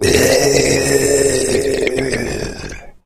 fracture_attack_8.ogg